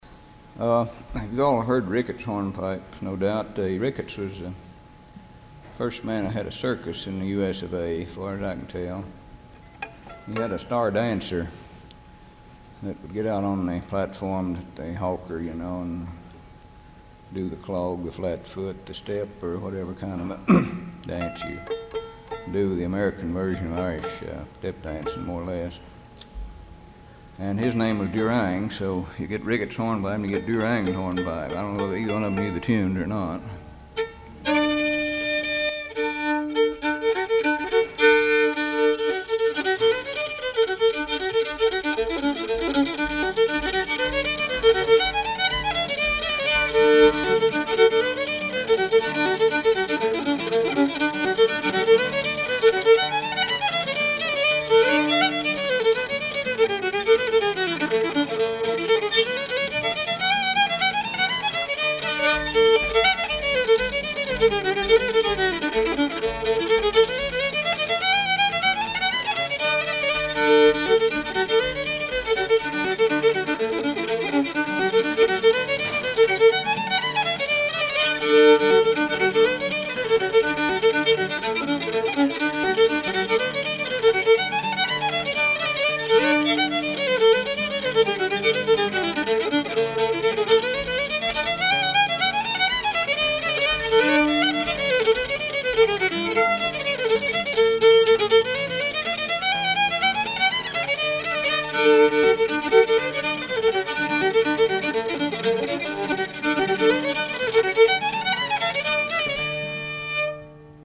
Here are several different versions from North American musical cultures: two teaching videos (American and Canadian); a performance video (traditional African-American string band); and archive reel-to-reel audio of a West Virginia fiddler.
fiddle